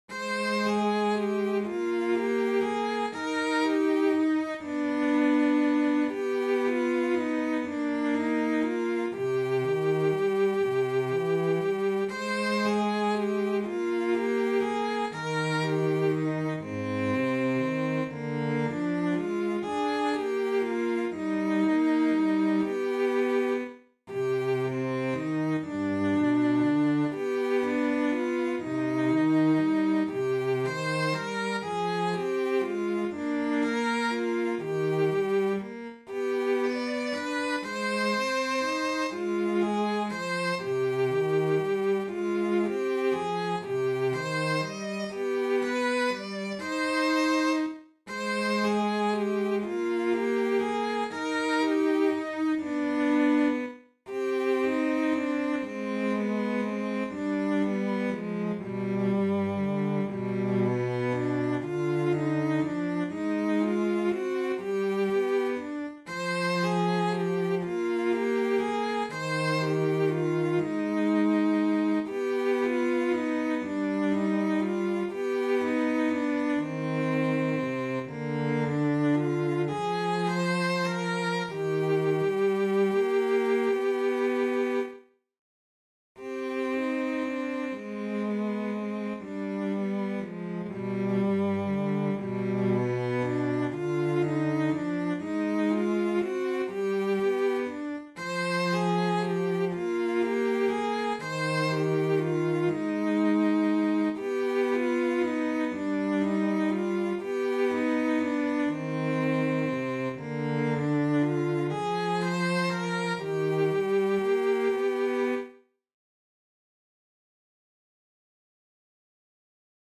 Elama-ihmetta-on-sellot.mp3